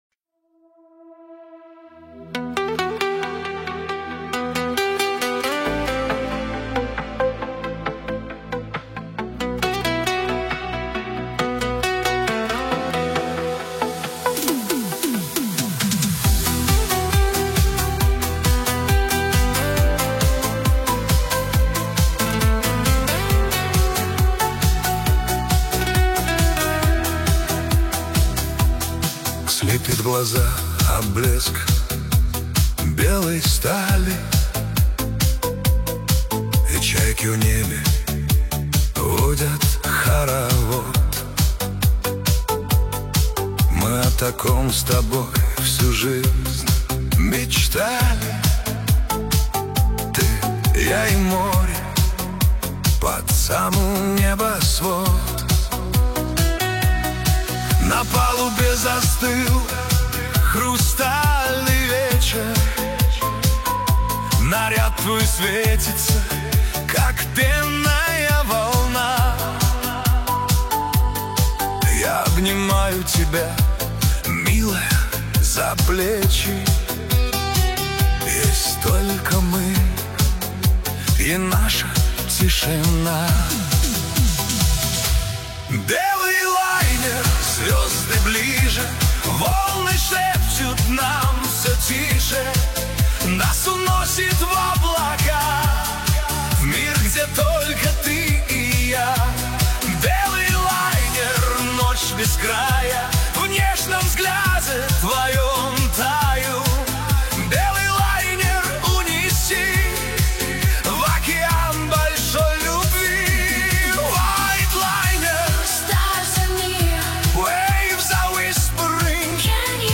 Качество: 320 kbps, stereo
Поп музыка, Русские треки, 2026